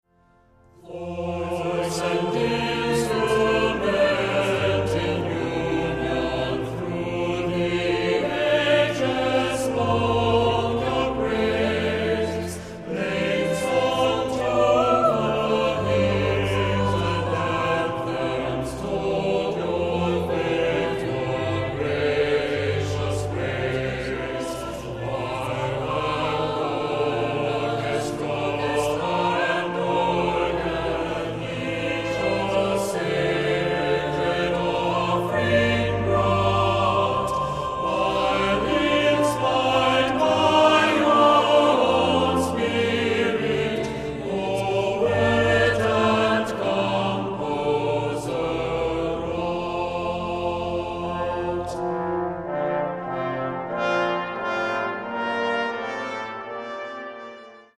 Voicing: SATB; Assembly